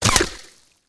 auto_hit_liquid1.wav